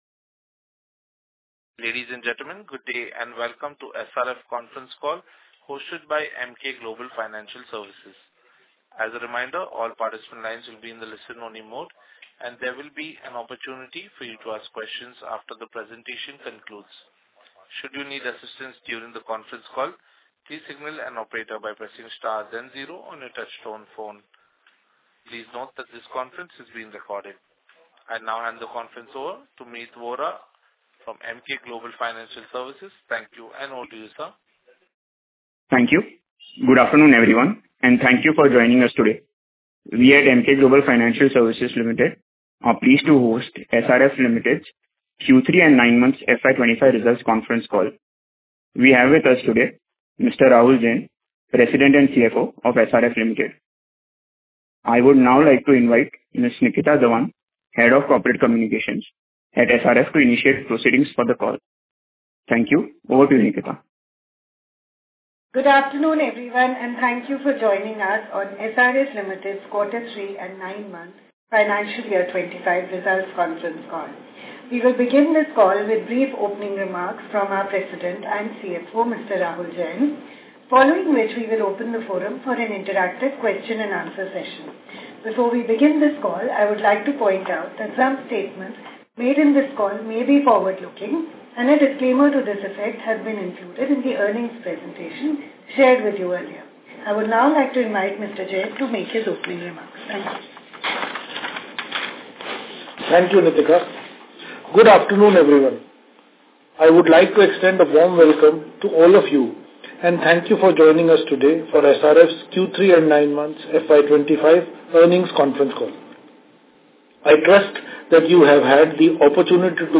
Concalls
SRF-Q3-FY25-Earnings-Call-Audio.mp3